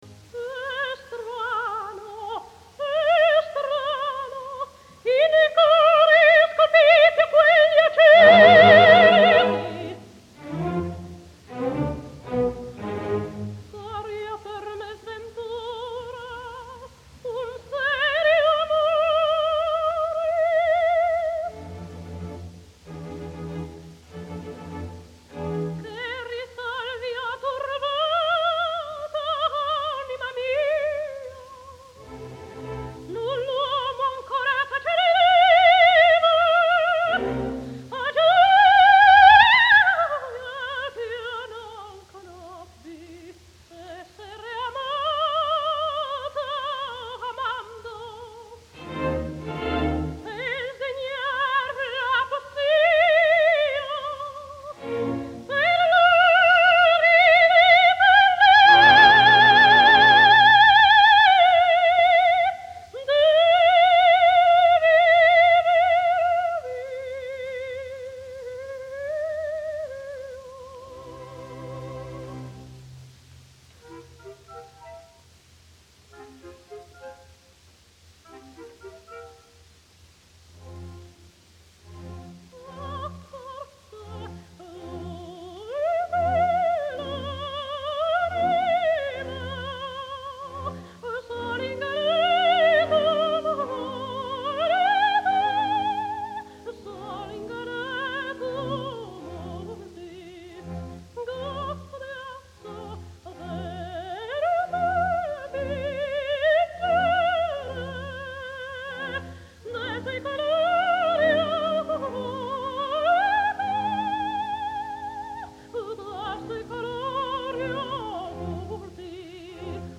120 лет со дня рождения испанской певицы (сопрано) Мерседес Капсир (Mercedes Capsir) !!!!!